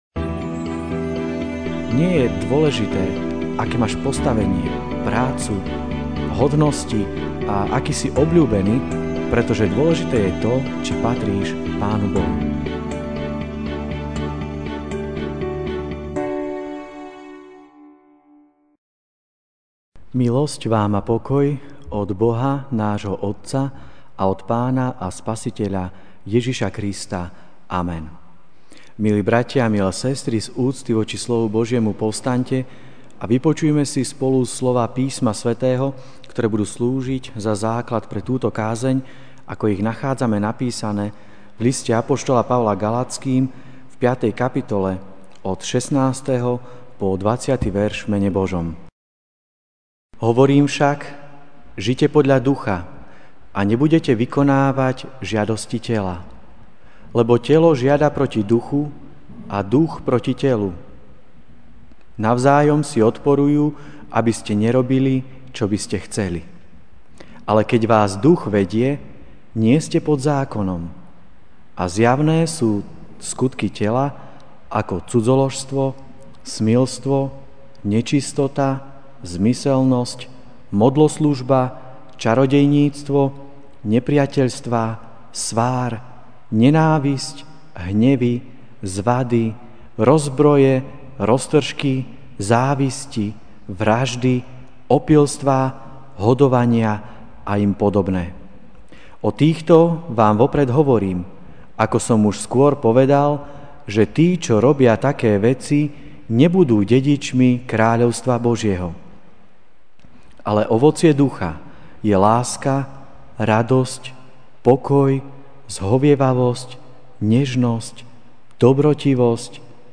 Večerná kázeň: Sloboda (Gal. 5, 16-21) Hovorím však: Žite podľa Ducha a nebudete vykonávať žiadosti tela.